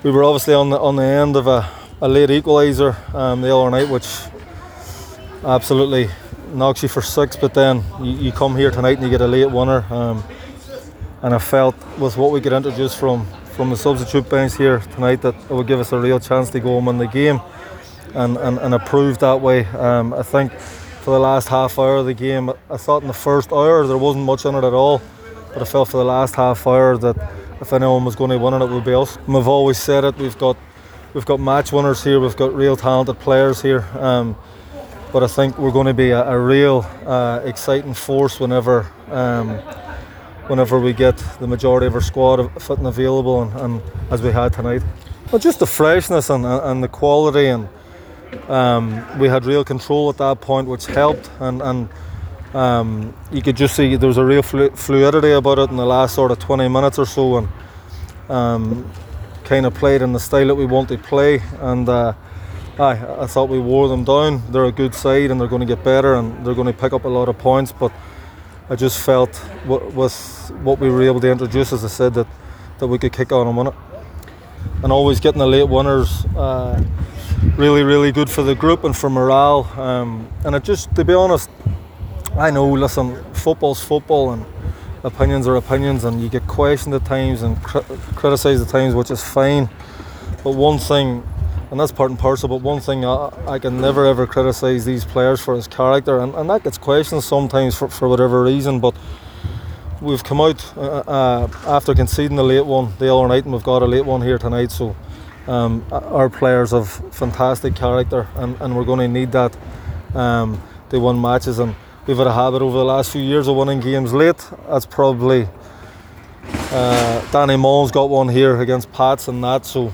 After the game, City manager Ruaidhri Higgins spoke to the assembled press…